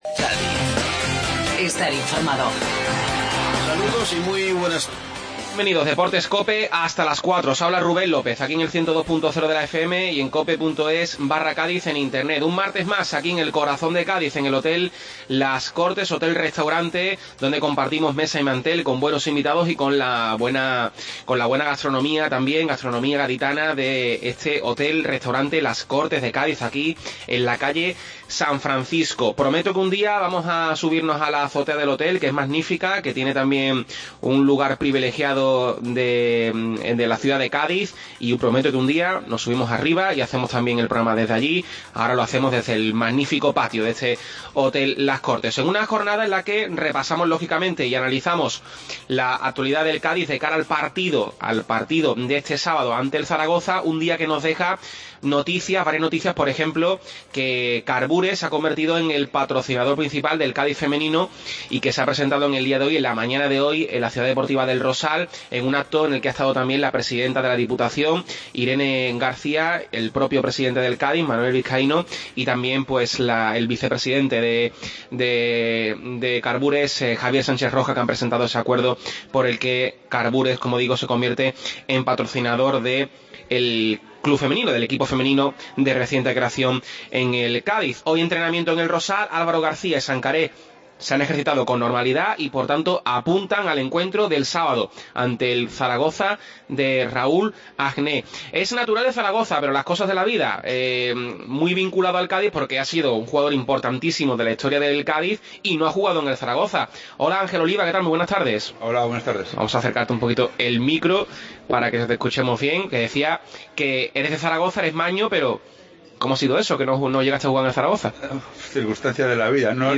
Desde el Hotel Las Cortes tertulia